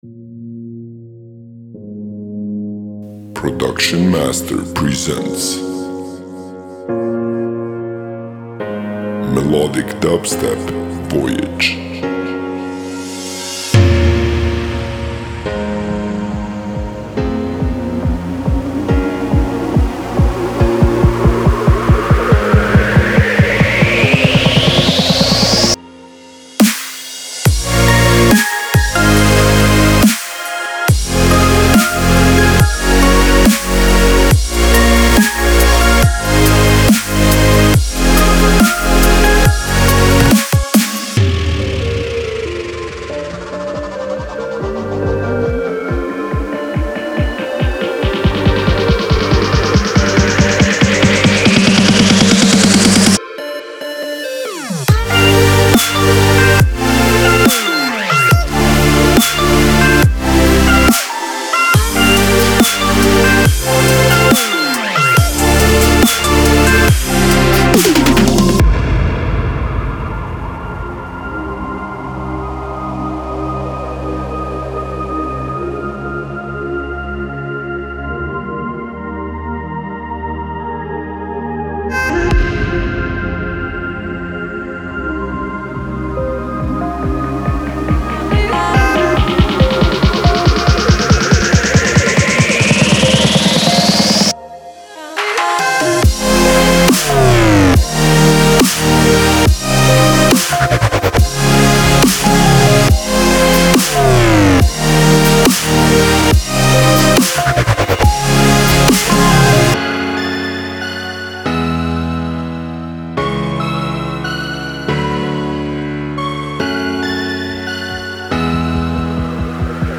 •109鼓一发
•22个旋律循环和优美的和弦
•17个未来派声乐排骨
•10个迷人的钢琴循环
•11个低音循环
•3个环境垫